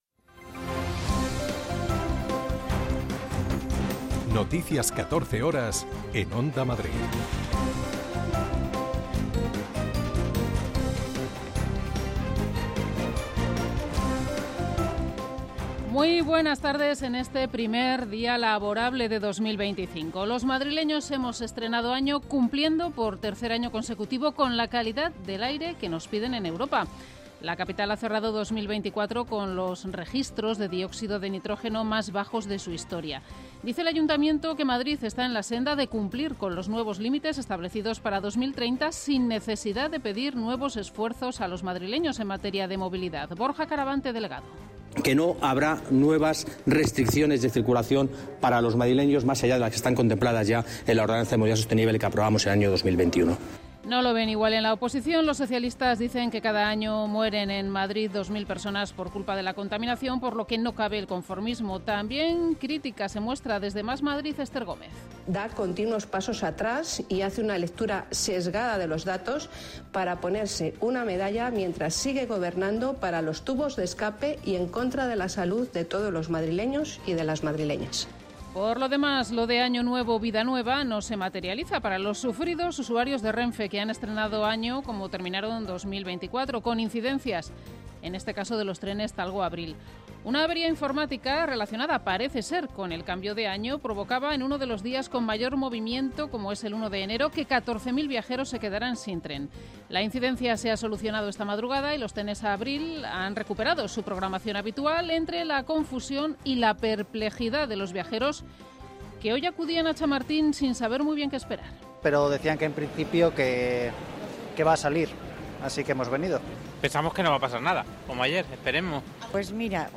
Noticias 14 horas 02.01.2025